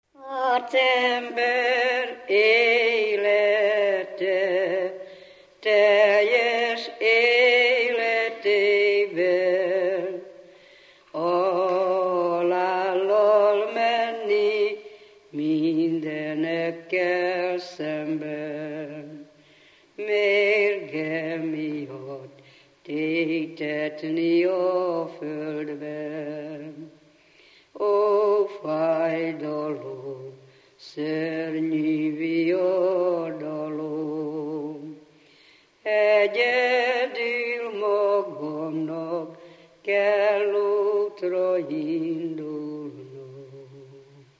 Alföld - Bihar vm. - Csökmő
Stílus: 4. Sirató stílusú dallamok
Kadencia: 4 (2) 2 1